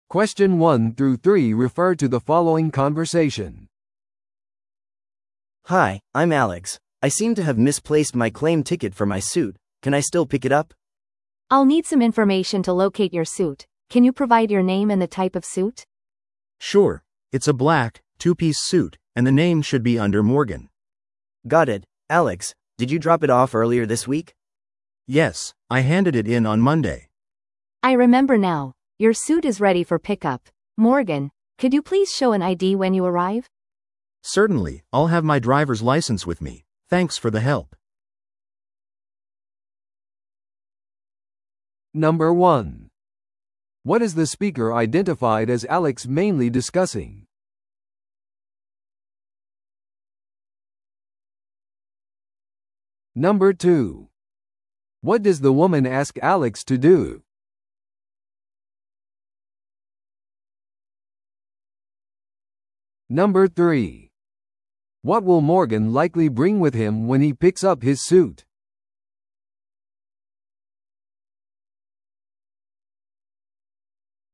No.2. What does the woman ask Alex to do?